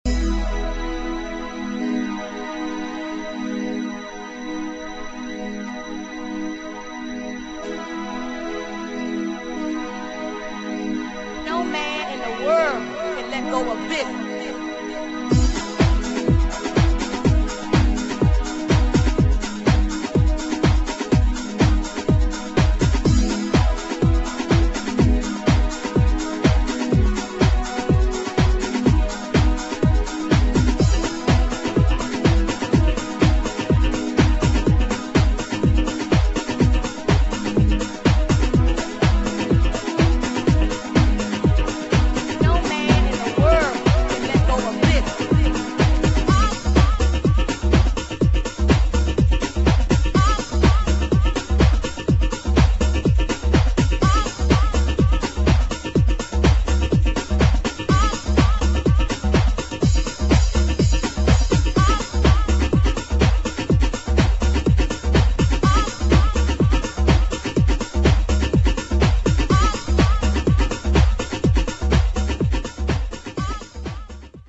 [ HOUSE / GARAGE HOUSE ]